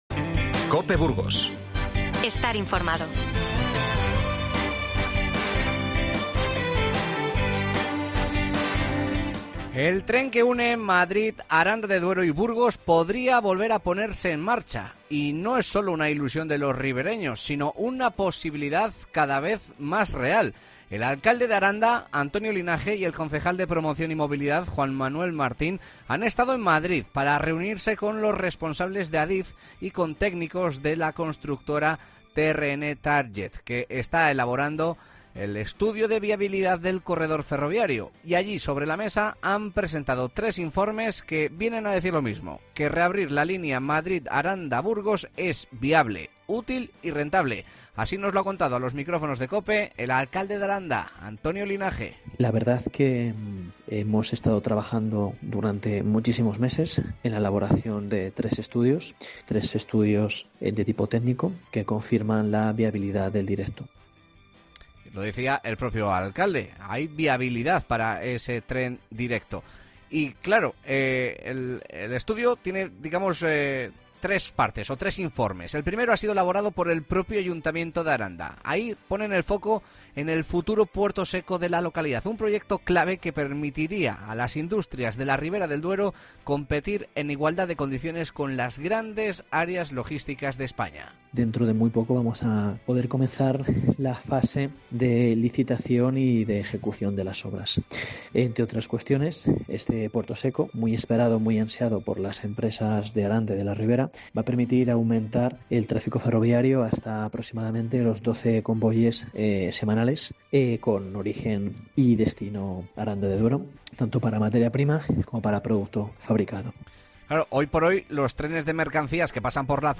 El alcalde de Aranda de Duero defiende la viabilidad del tren directo Madrid-Aranda-Burgos